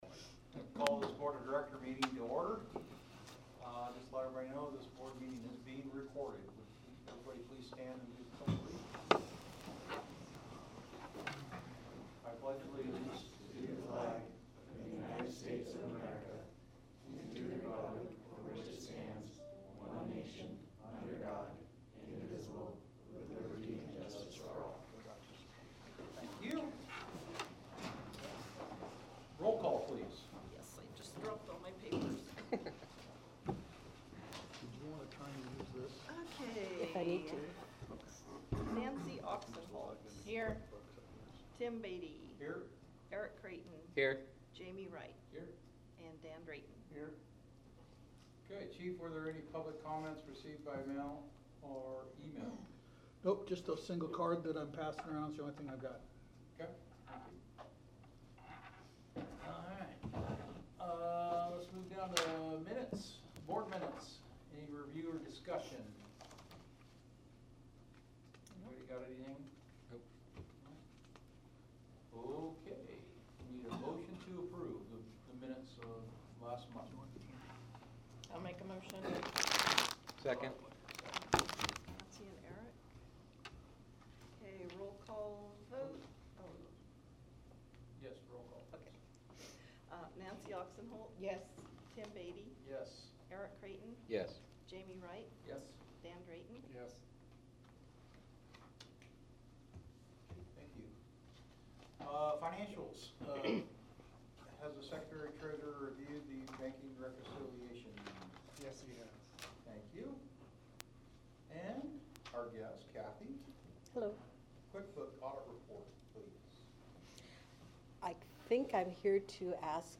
Board Meeting